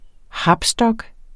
Udtale [ ˈhɑbsˌdʌg ]